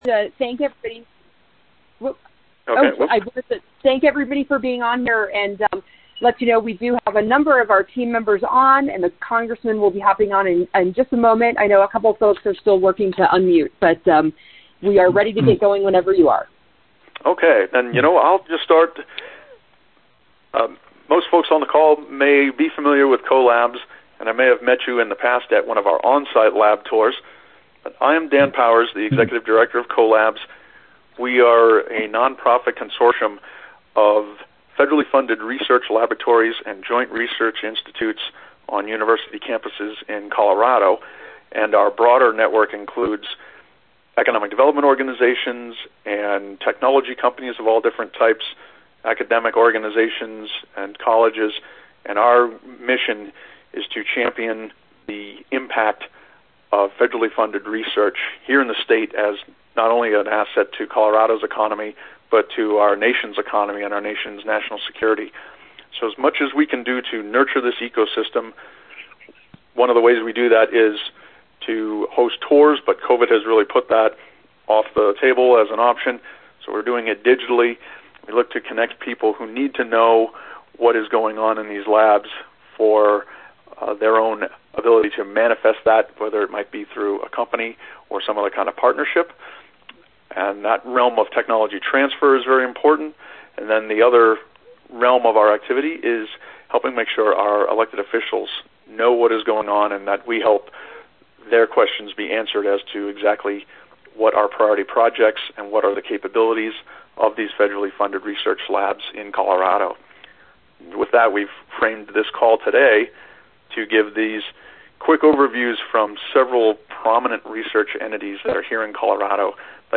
CO-LABS has organized a conference call for U.S. Representative Joe Neguse (CO CD-2) to hear from several federally-funded research labs in Colorado. The themes for this call are related to 1) research around climate change, extreme weather, and related environmental assessments with relevance to the Biden Administration’s stated climate change policy vision; and 2) advanced digital communications technology research.